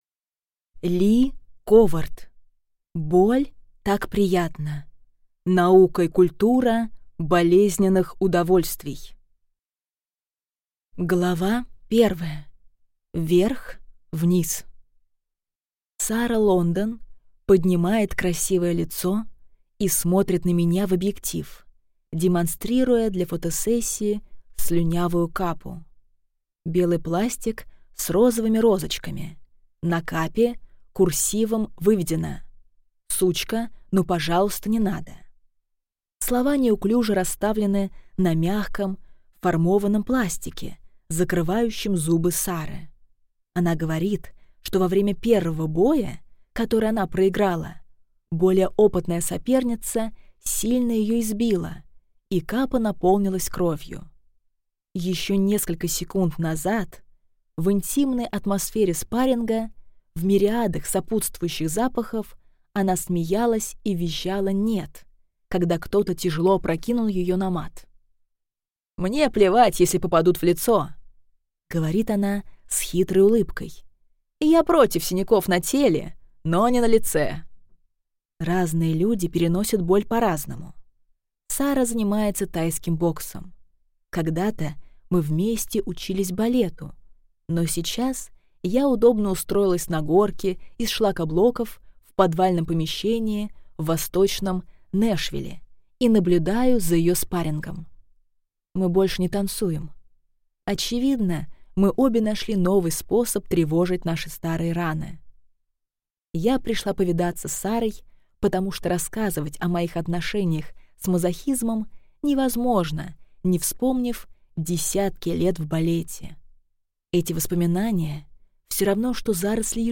Аудиокнига Боль так приятна. Наука и культура болезненных удовольствий | Библиотека аудиокниг